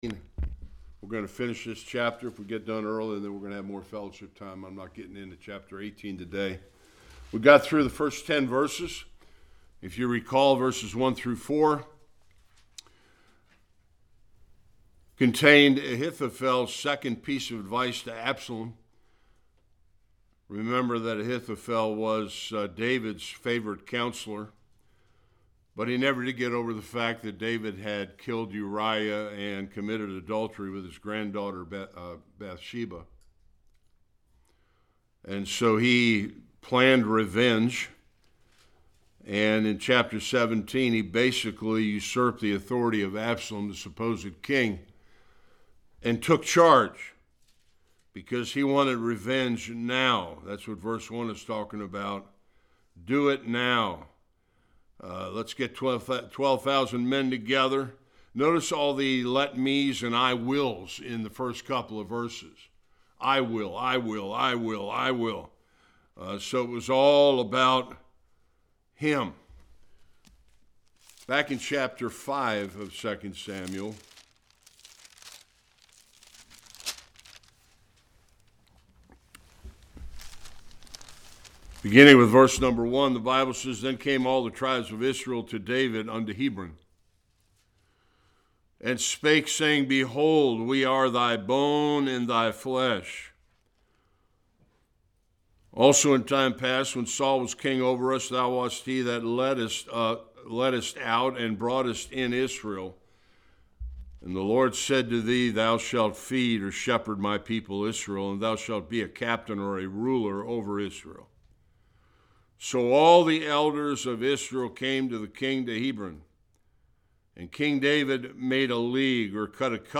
11-29 Service Type: Sunday School Absalom has usurped his father David’s throne and is now coming with his army to kill him.